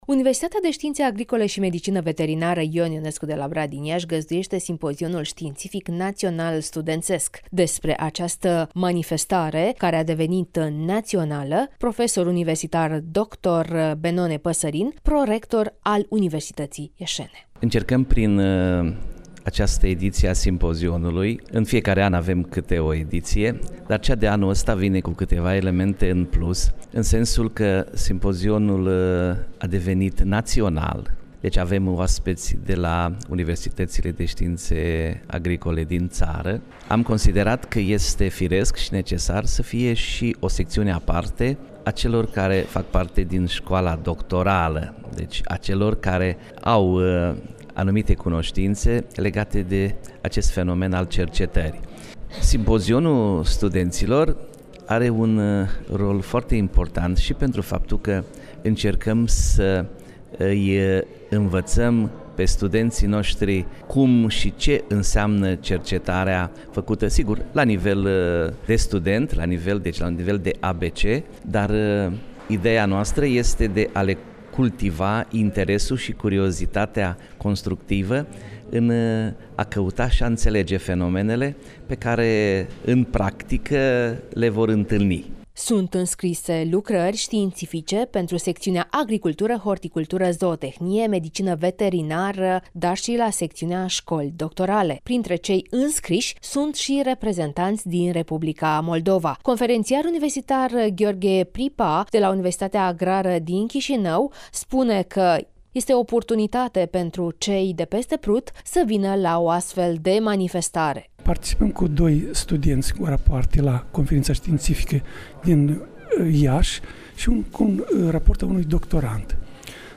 (REPORTAJ) Simpozion Ştiinţific Național Studenţesc la Agronomie